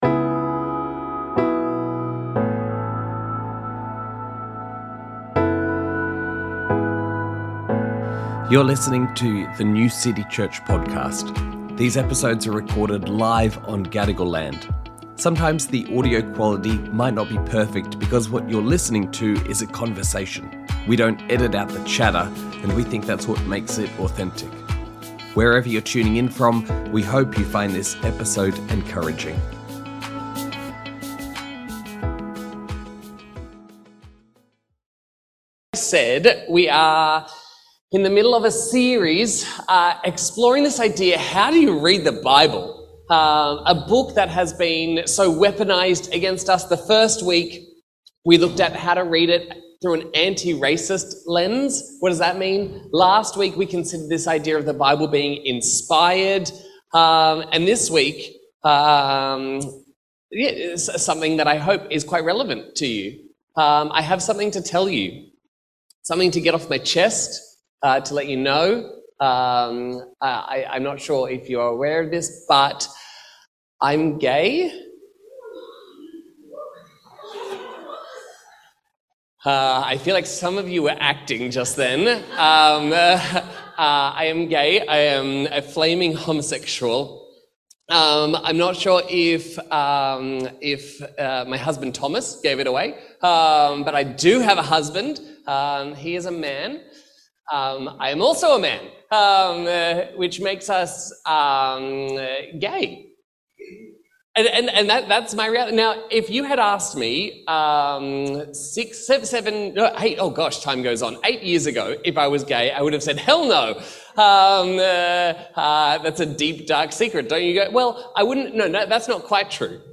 Sermons | New City Church